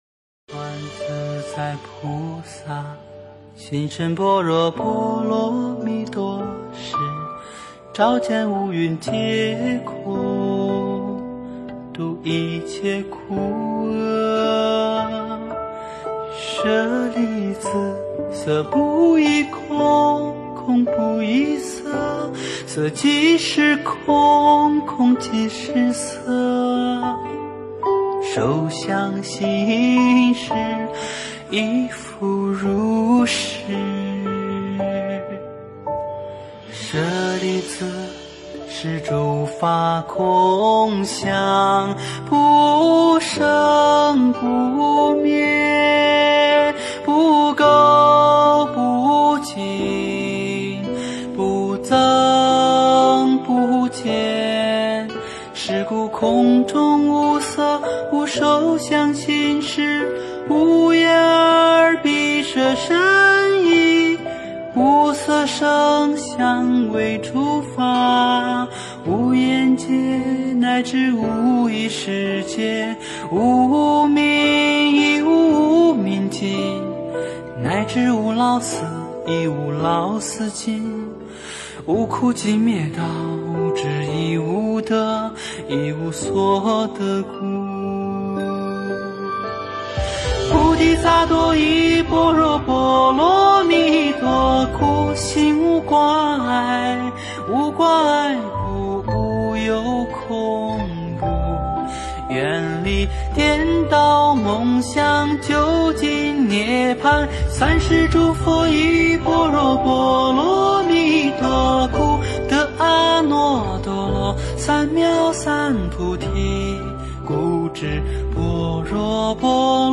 般若波罗密多心经(唱颂)
诵经 般若波罗密多心经(唱颂
佛音 诵经 佛教音乐 返回列表 上一篇： 善天女咒 下一篇： 千手观音 相关文章 南无本师释迦牟尼佛--中国佛学院法师 南无本师释迦牟尼佛--中国佛学院法师...